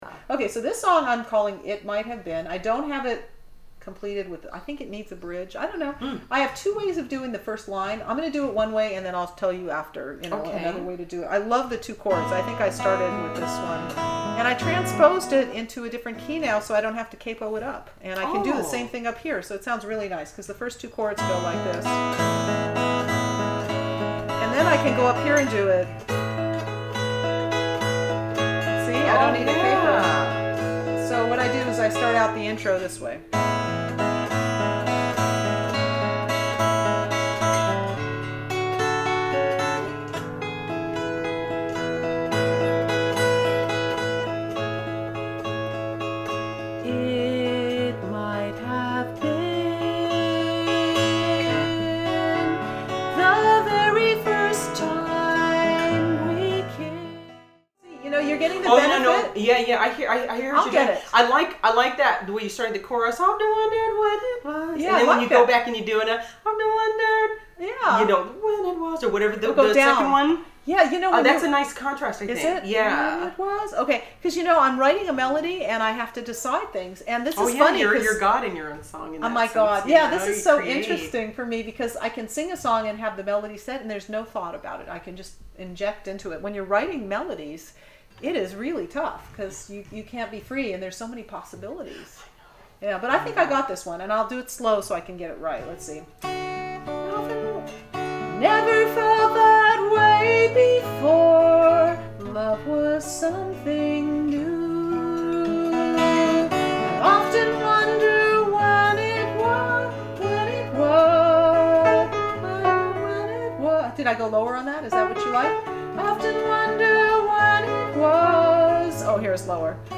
It Might Have Been Home Recording
I became quite excited to embrace my vocal range – I went for a super, high note because it just felt like it belonged in my song.
I recorded the guitar track fairly quickly – it wasn’t too complicated.